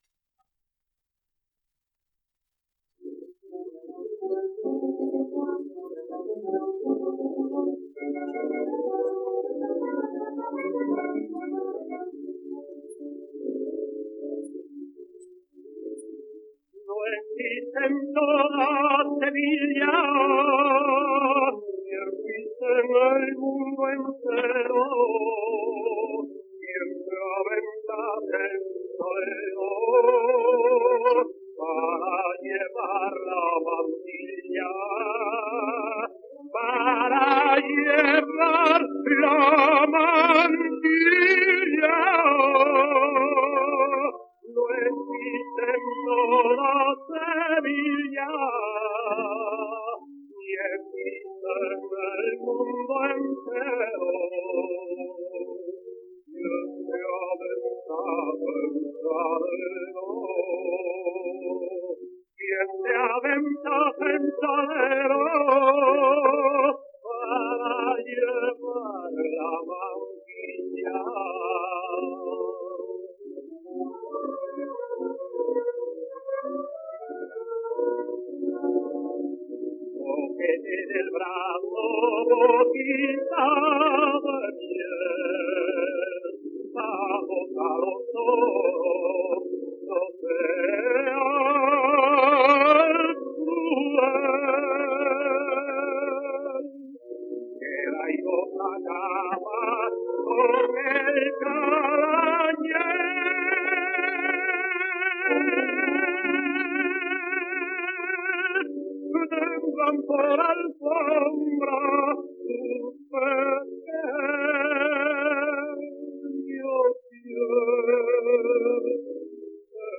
La mantilla : canción (sonido remasterizado)